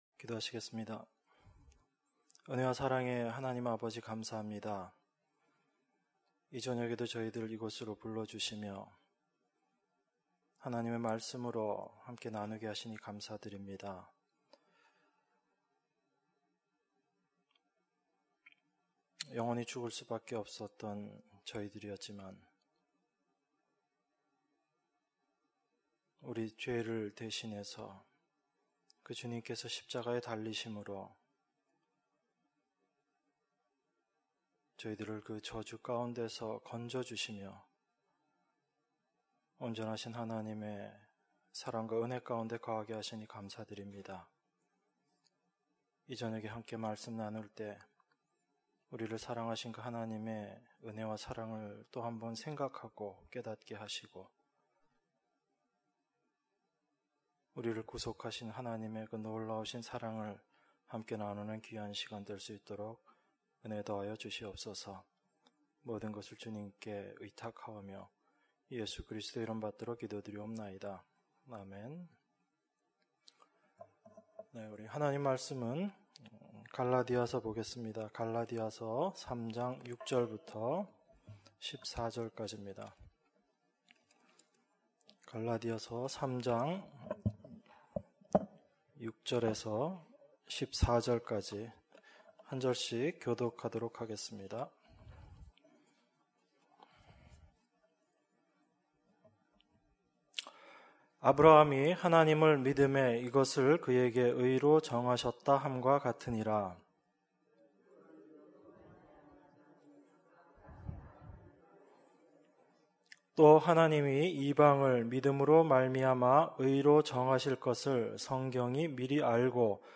수요예배 - 갈라디아서 3장 6절~14절